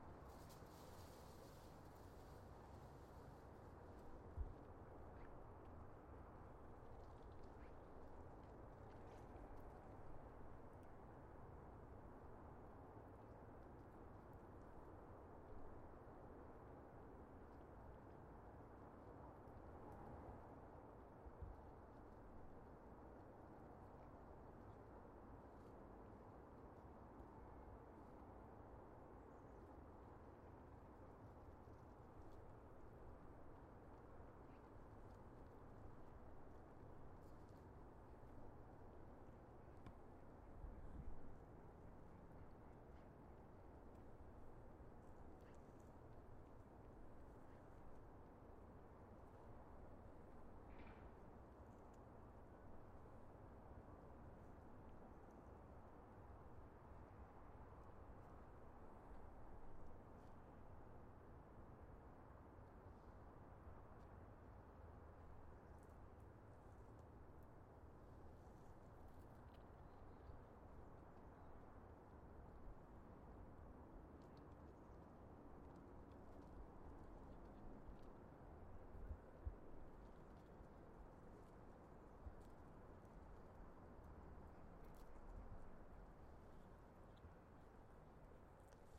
黑暗环境 " 黑暗环境 015
描述：使用fl studio和audacity制作
Tag: 背景音 白噪声 音景 氛围 环境 背景 ATMO ruido 噪音